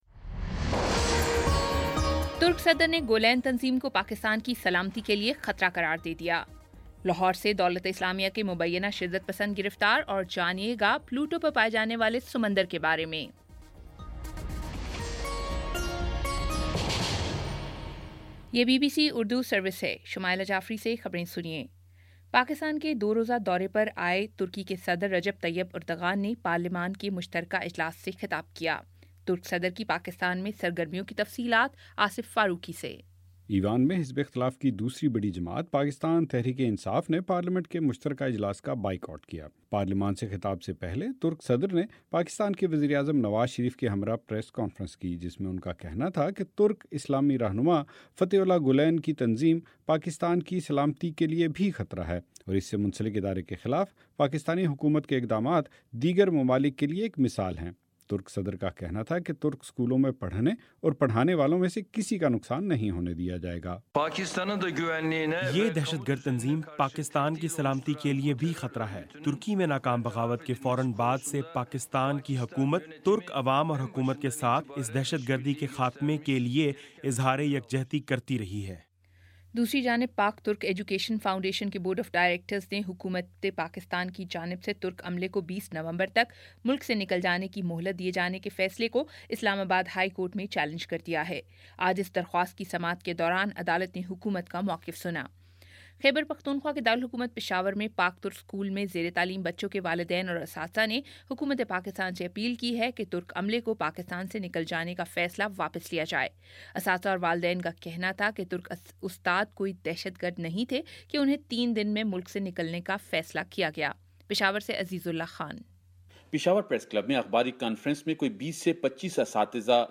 نومبر 17 : شام چھ بجے کا نیوز بُلیٹن